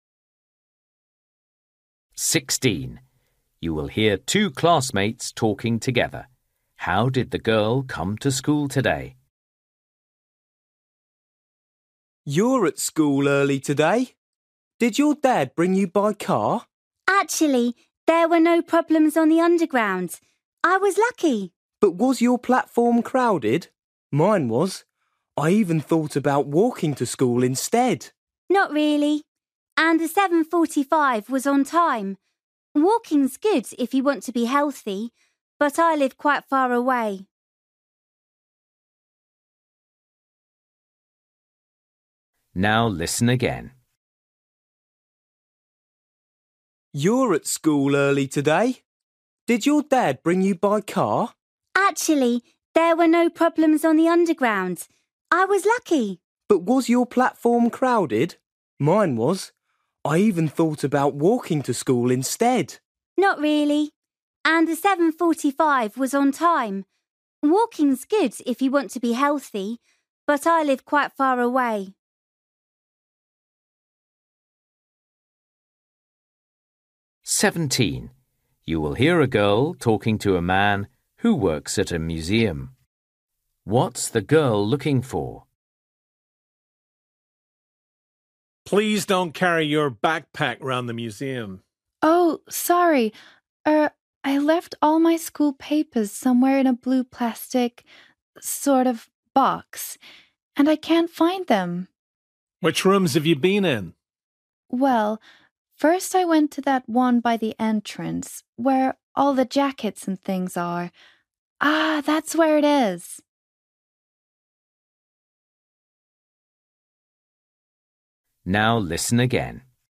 Listening: everyday short conversations
16   You will hear two classmates talking together. How did the girl come to school today?
17   You will hear a girl talking to a man who works at a museum. What’s the girl looking for?
18   You will hear a boy talking about learning French. How did he improve his French?
20   You will hear a headteacher talking to the whole school. What’s new at the school this year?